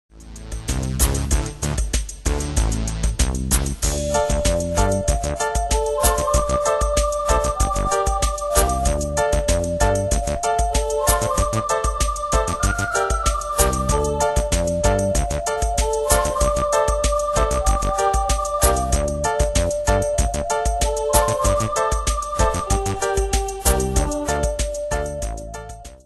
Style: PopAnglo Année/Year: 1993 Tempo: 95 Durée/Time: 3.35
Pro Backing Tracks